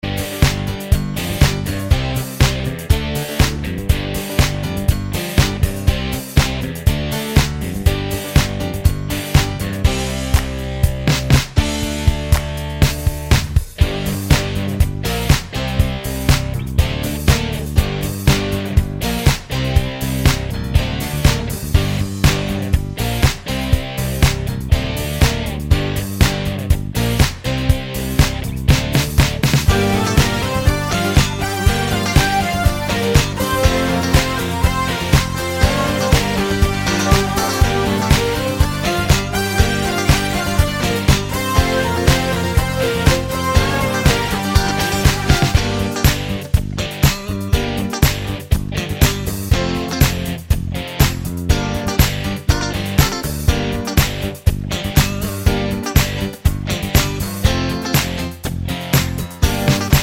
no Backing Vocals Disco 3:54 Buy £1.50